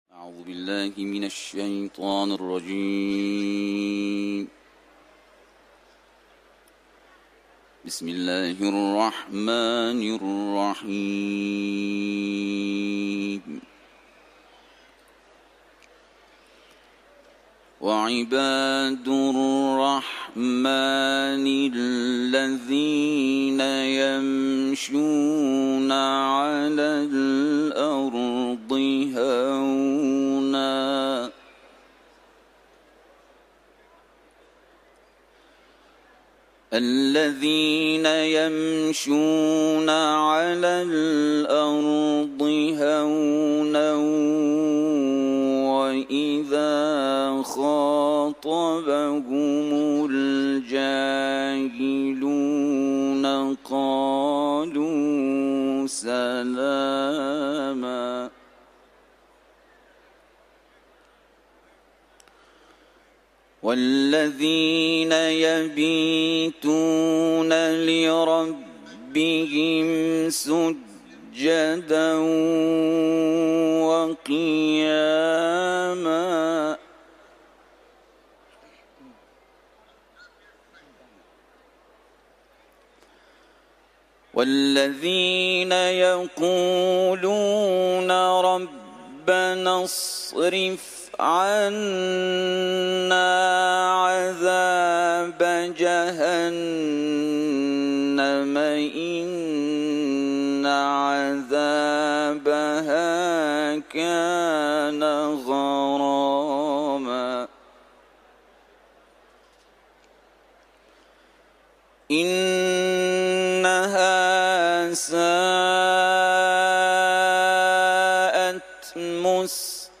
تلاوت قرآن ، سوره فرقان